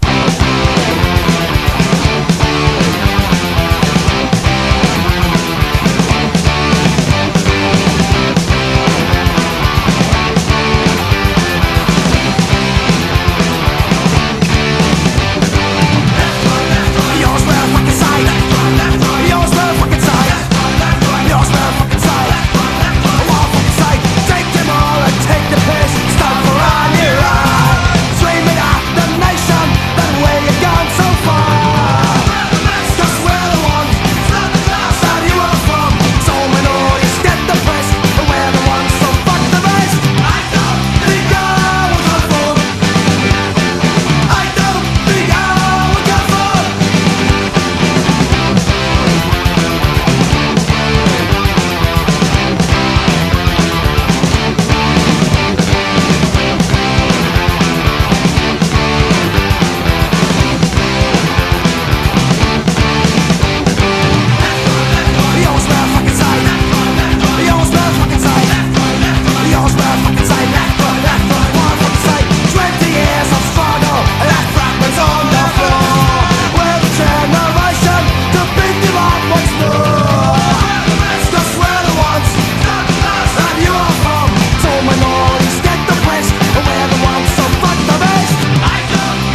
ROCK / PUNK / 80'S～ / STREET PUNK
US STREET PUNKバンド！